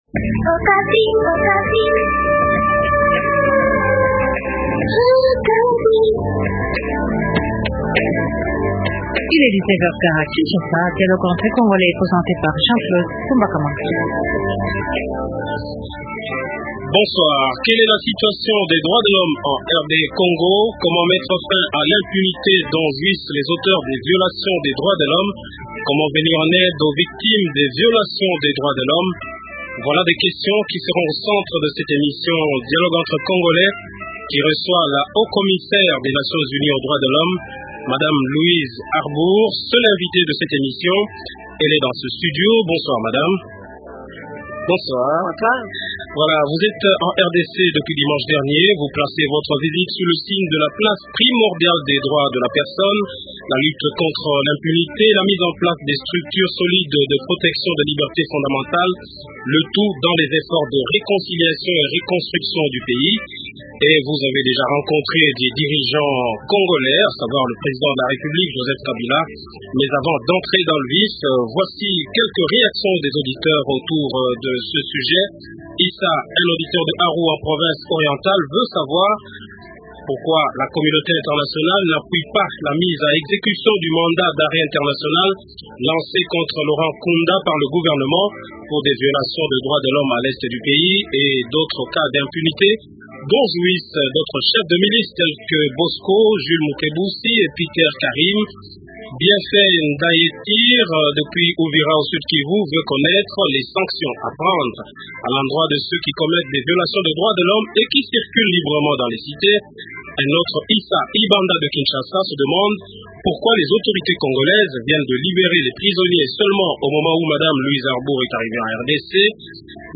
Réponses à ces questions dans Dialogue entre congolais. Invité : Louise Arbour, Haut commissaire des Nations Unies aux droits de l’homme.